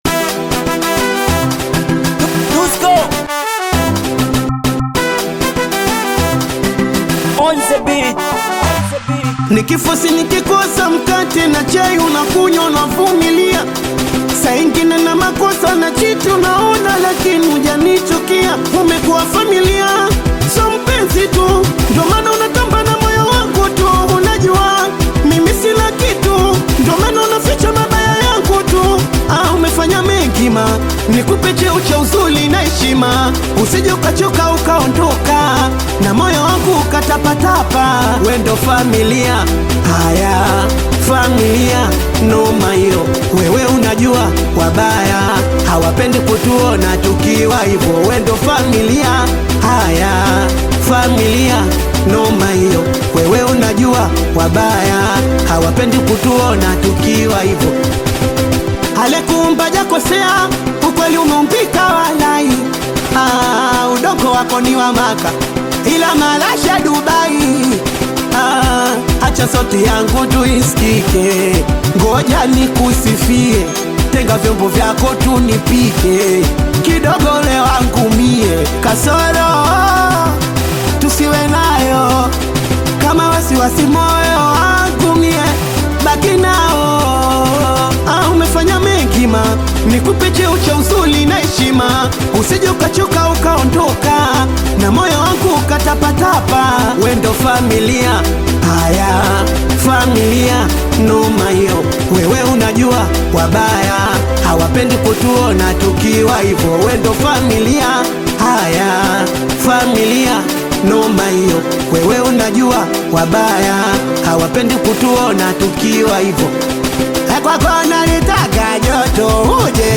AudioSingeli
Singeli anthem